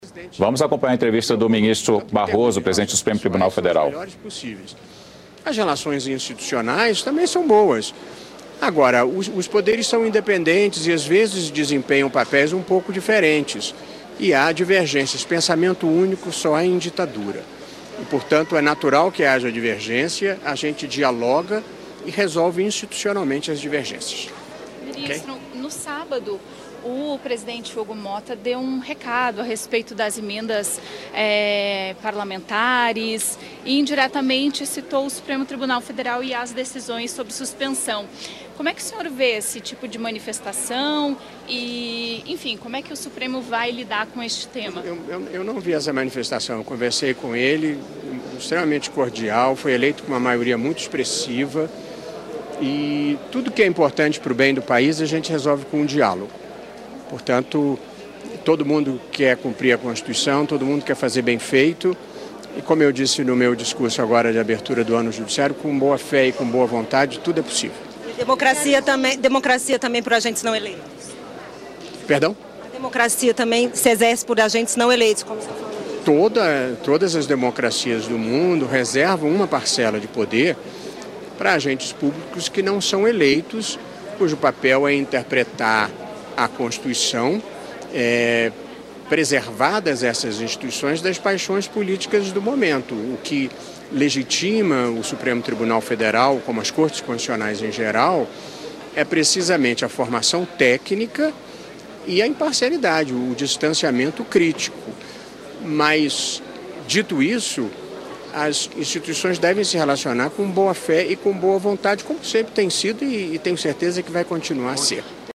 Mas antes, ele falou com a reportagem da Rádio e TV Senado.